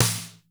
VERY SNARE.wav